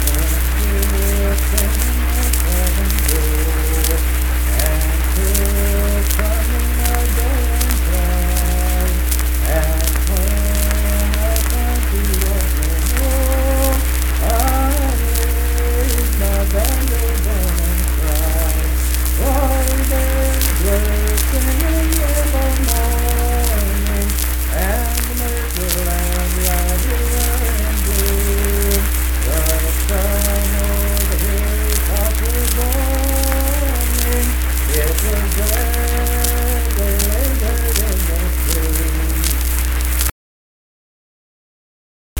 Unaccompanied vocal music
Performed in Kanawha Head, Upshur County, WV.
Voice (sung)